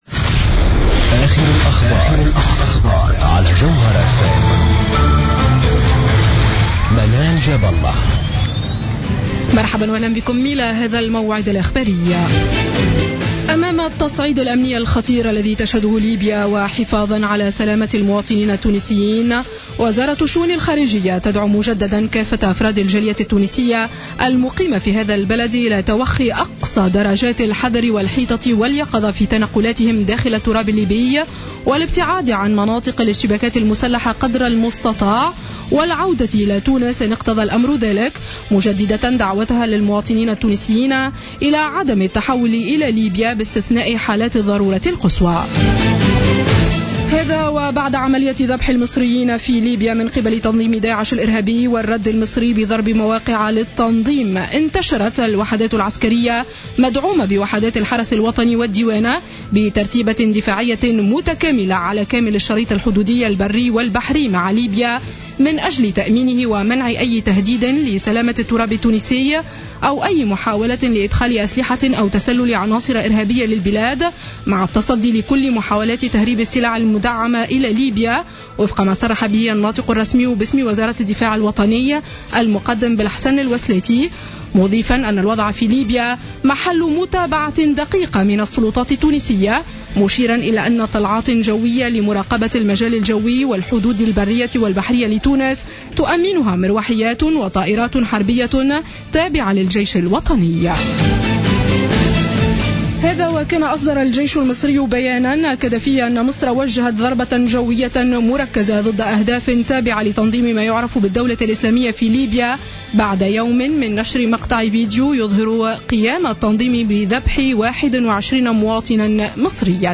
نشرة أخبار السابعة مساء ليوم الاثنين 16 فيفري 2015